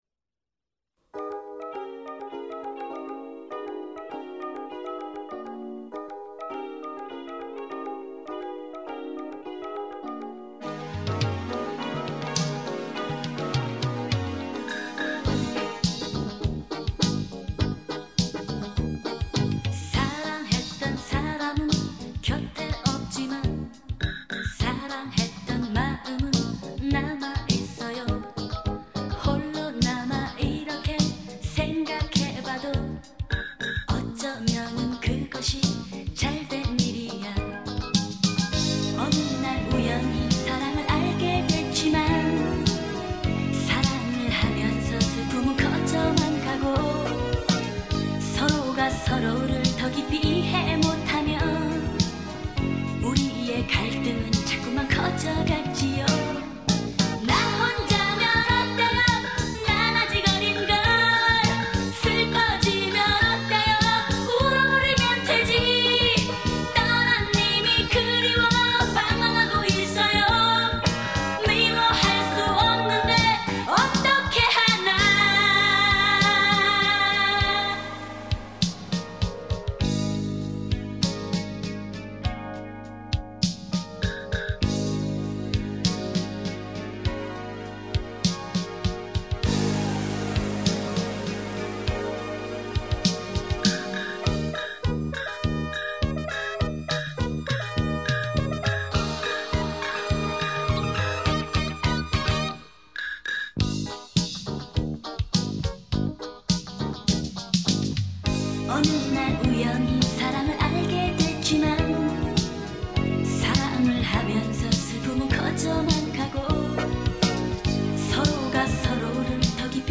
대한민국의 여성 싱어송라이터, 작사가, 작곡가, 음반 프로듀서, 배우이다.